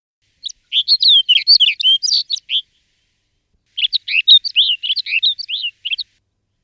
Bruant des neiges
Plectrophenax nivalis